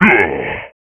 pain.wav